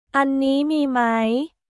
アンニー ミー マイ？